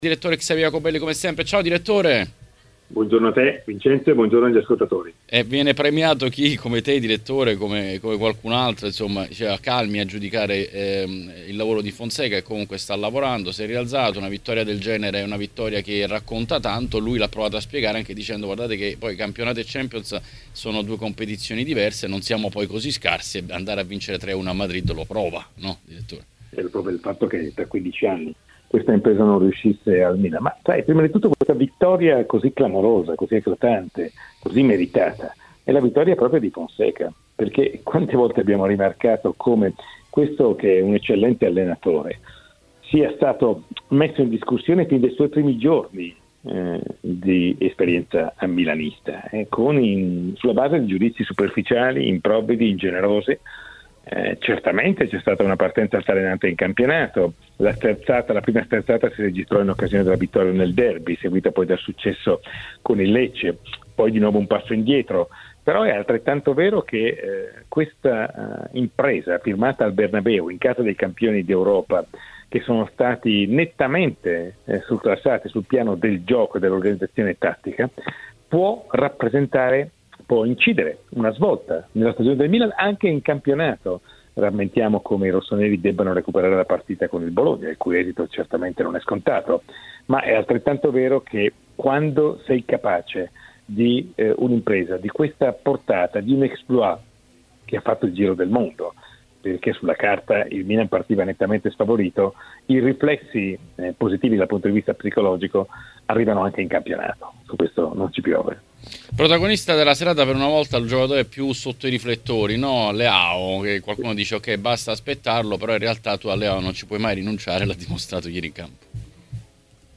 Ospite di oggi il direttore Xavier Jacobelli.
Le Interviste